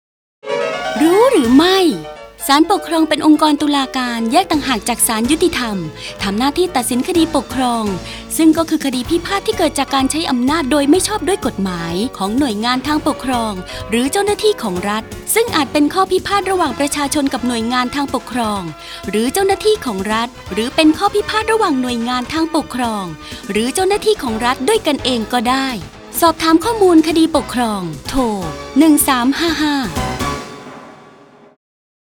คำสำคัญ : แนะนำหน่วยงาน, สำนักงานศาลปกครอง, อำนาจหน้าที่, คดีปกครองชวนรู้, ศาลปกครอง, สารคดีวิทยุ